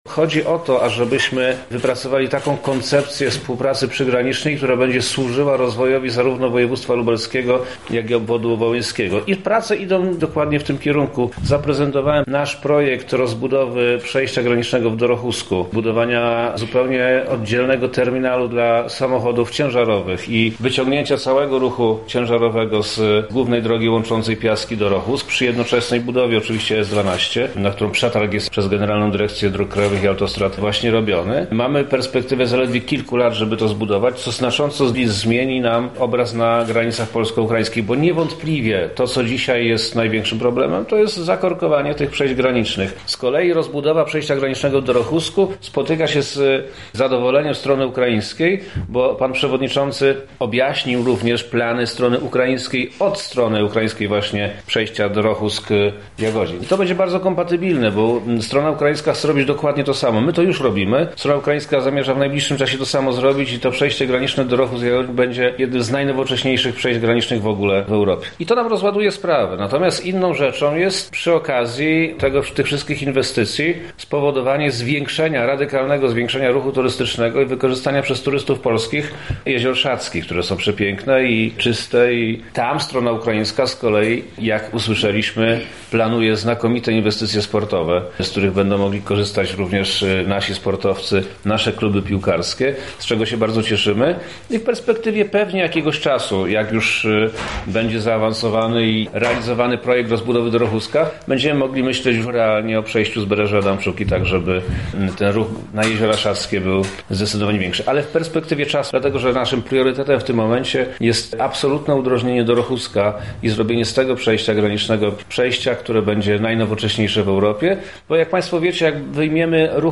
Za nami konferencja z udziałem Wojewody Lubelskiego. Tematem były stosunki polsko-ukraińskie
O pomysłach i priorytetach mówi Wojewoda Lubelski, Przemysław Czarnek: